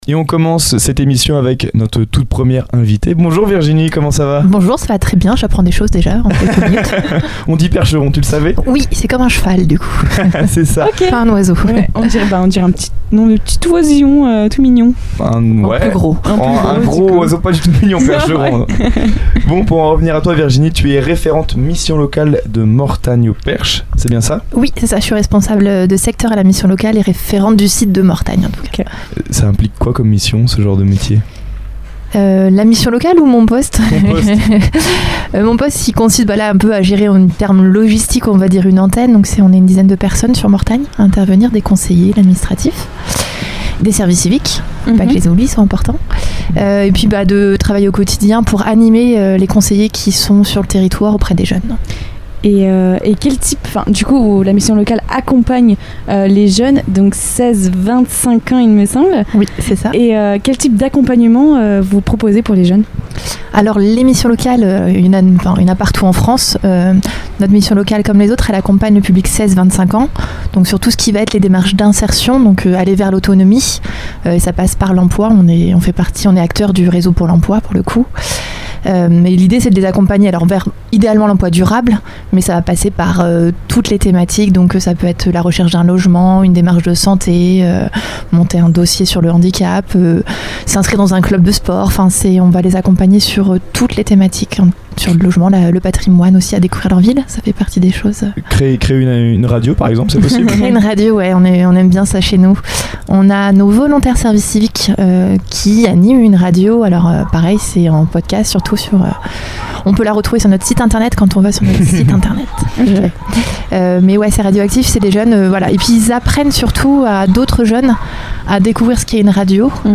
Une interview engagée et humaine qui met en lumière un dispositif essentiel pour soutenir les jeunes et dynamiser le territoire.